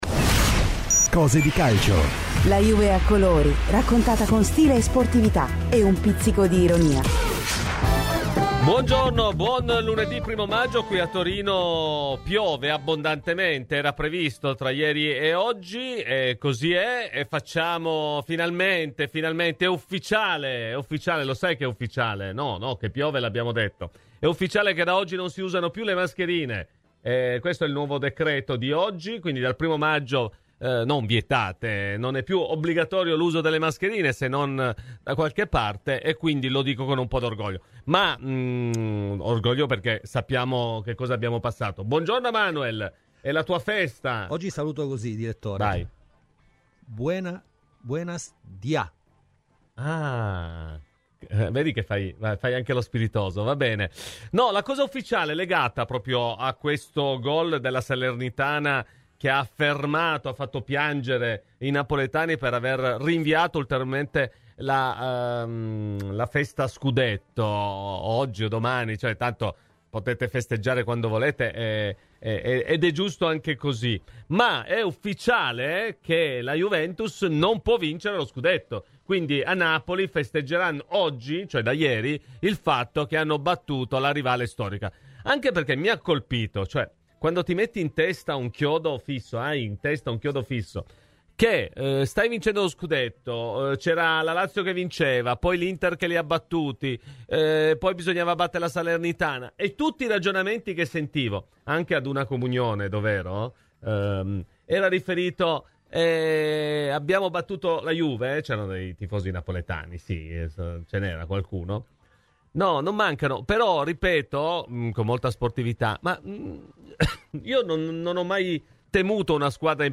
Radio Bianconera